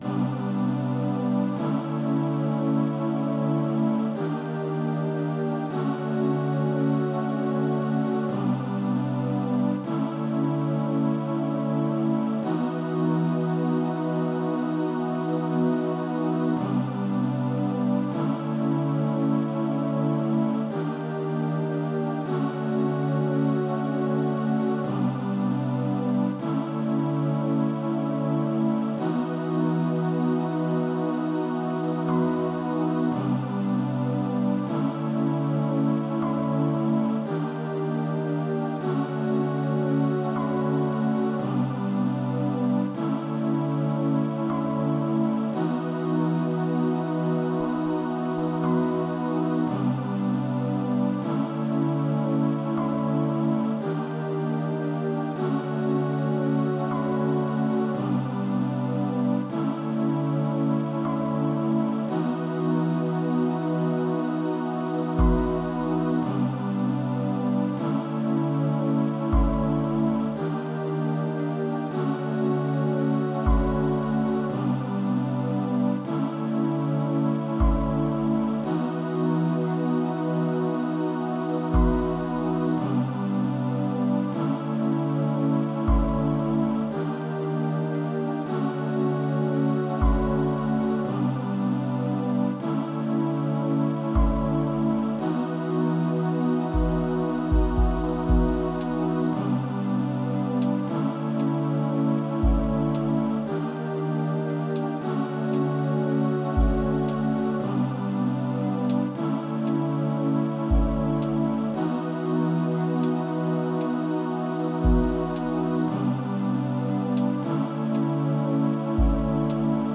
ボーナストラック – BGM（朝用） オンラインでお聞きになりたい方は再生ボタンを押してください。